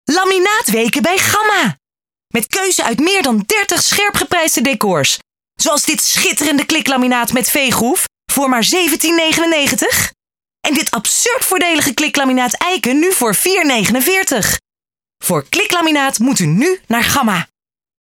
Commercials:
Gamma (wervend):